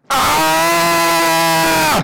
AAAAAAAAAAAAAAAAAAAAAAA.wav